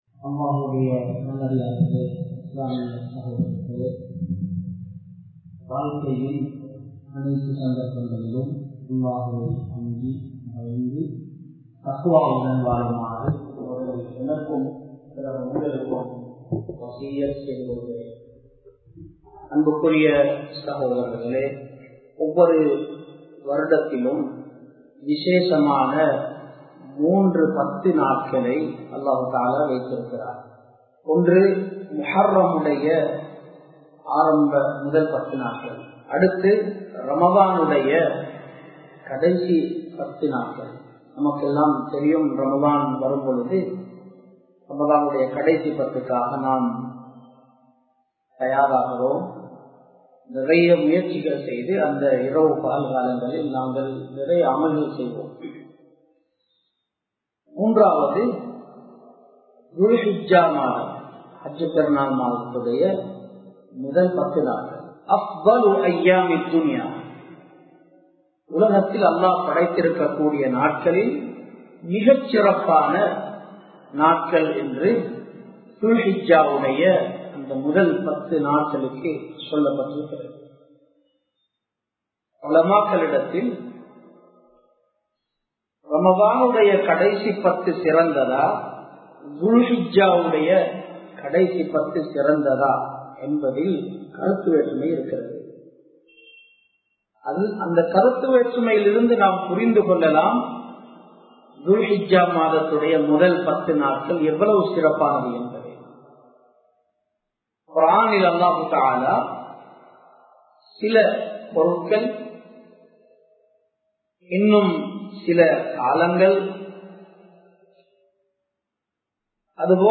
துல்ஹிஜ்ஜாவின் முதல் 10 நாட்களின் சிறப்புகள் (Rewards on 10 Holy Days of Dhull Hijjah) | Audio Bayans | All Ceylon Muslim Youth Community | Addalaichenai
Colombo 11, Samman Kottu Jumua Masjith (Red Masjith)